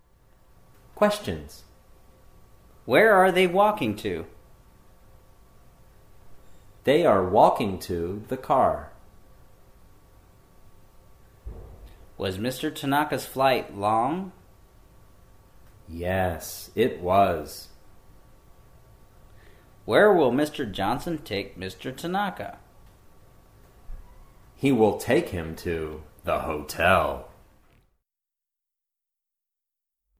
A business English dialog series (with questions and answers for beginning level learners)